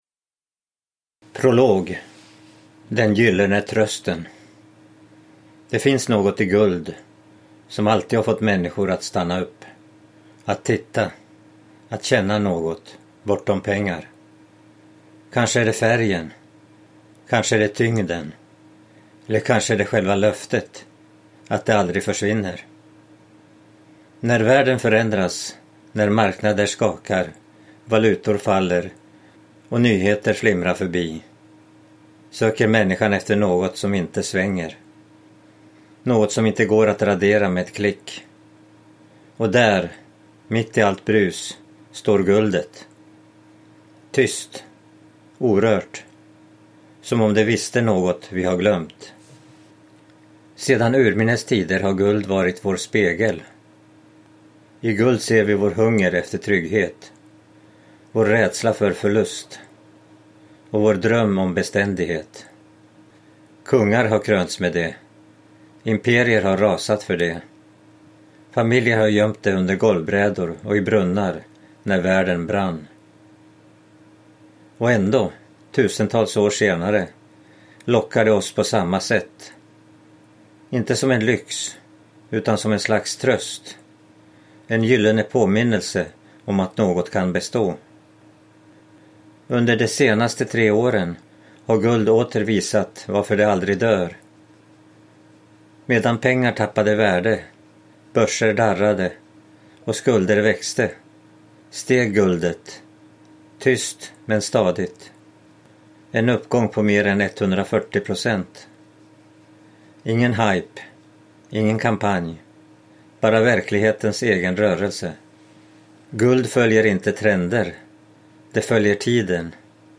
Ljudbok 139 kr